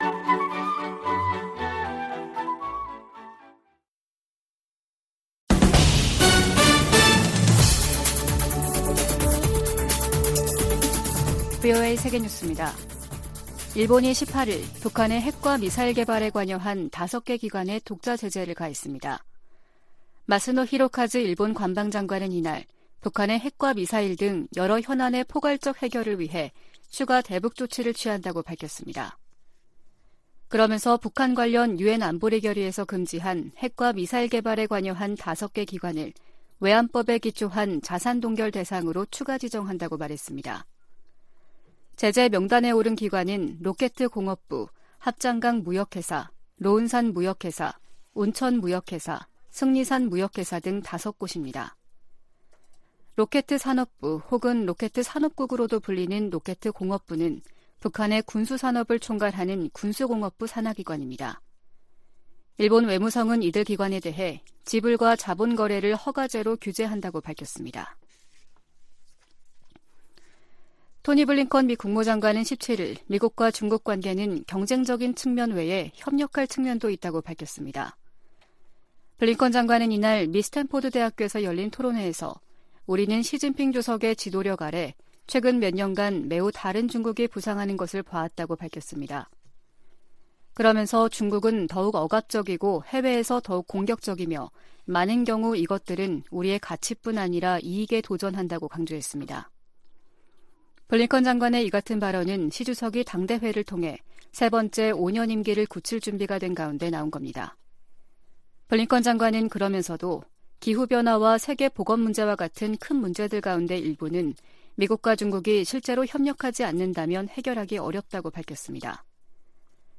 VOA 한국어 아침 뉴스 프로그램 '워싱턴 뉴스 광장' 2022년 10월 19일 방송입니다. 북한의 잇따른 도발은 무시당하지 않겠다는 의지와 강화된 미한일 안보 협력에 대한 반발에서 비롯됐다고 토니 블링컨 미 국무장관이 지적했습니다. 필립 골드버그 주한 미국대사는 전술핵 한반도 재배치론에 부정적 입장을 분명히 했습니다. 북한의 잇단 미사일 발사로 긴장이 고조되면서 일본 내 군사력 증강 여론이 강화되고 있다고 미국의 일본 전문가들이 지적했습니다.